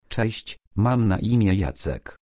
Male